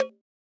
ORG Conga.wav